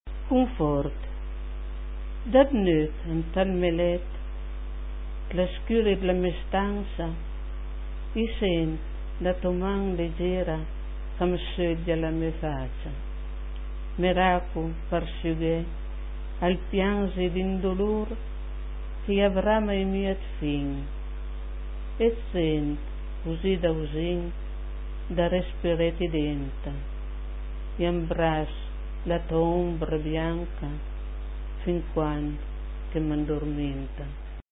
Cliché chi sùta par sénti la puizìa recità da l'autùr ...